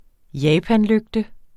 Udtale [ ˈjaːpan- ]